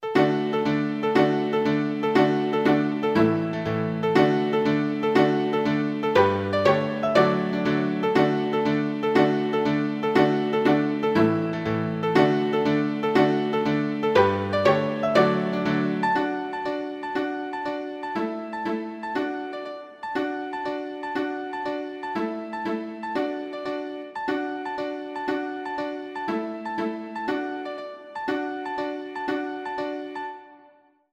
MIDIPark, Maria Hester, Divertimento for violin and piano, mm.1-8
(one subdominant chord appears in m. 4)